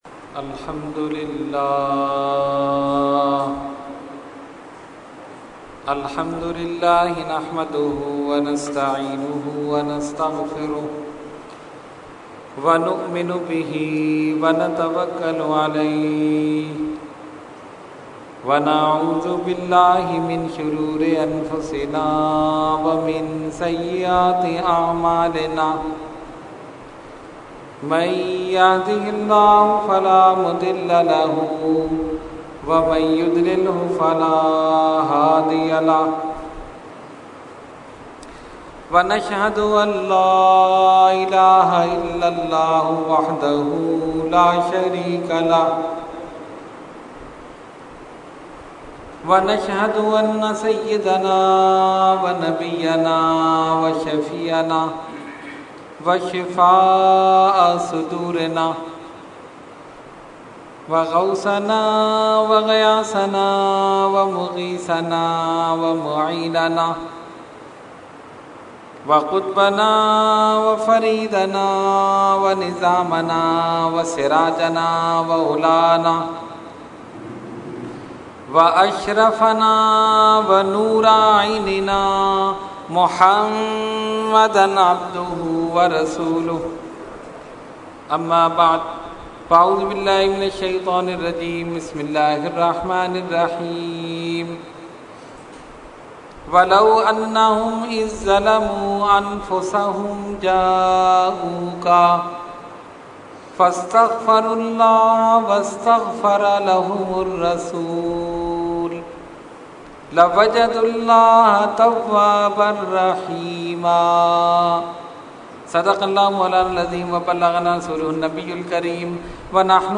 Category : Speech | Language : UrduEvent : Dars Quran Ghousia Masjid 24 June 2012